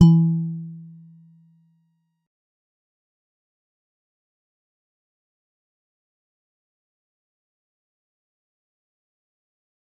G_Musicbox-E3-mf.wav